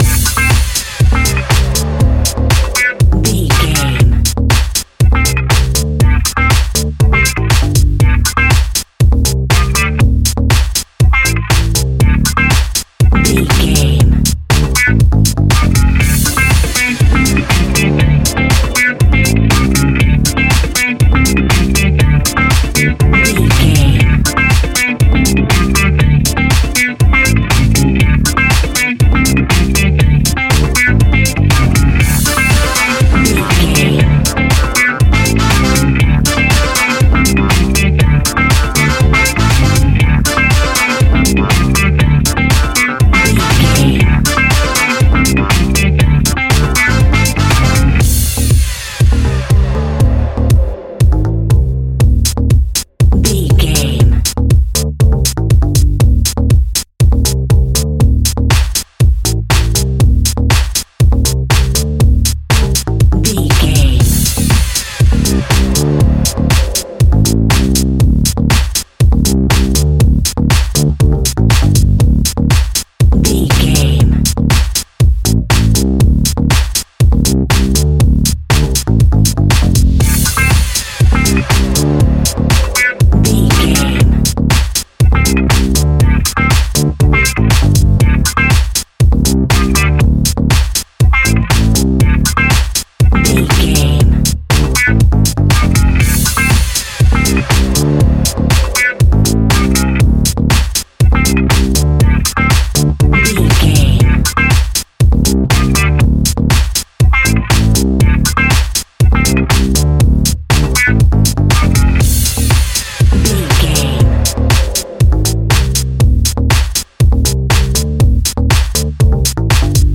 Aeolian/Minor
groovy
futuristic
hypnotic
uplifting
bass guitar
electric guitar
drums
synthesiser
funky house
disco house
electro funk
energetic
upbeat
synth leads
Synth Pads
synth bass
drum machines